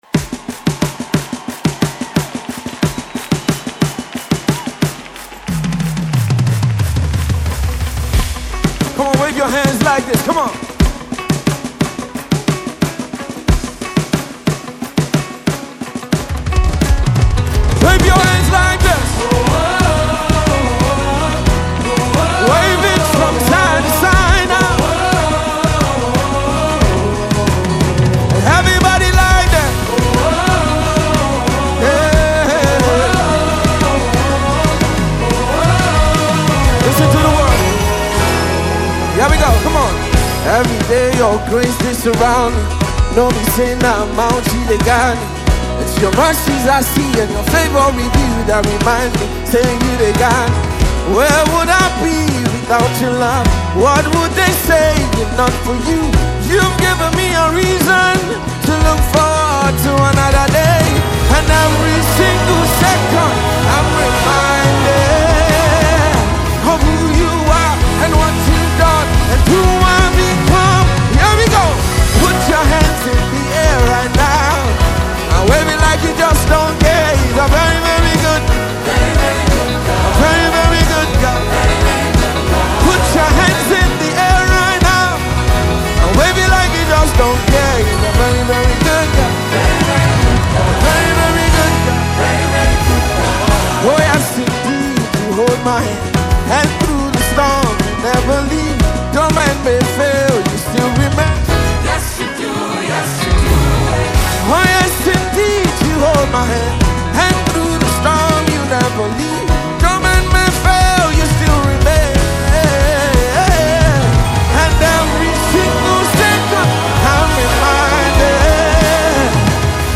Nigeria Gospel Music
a powerful worship song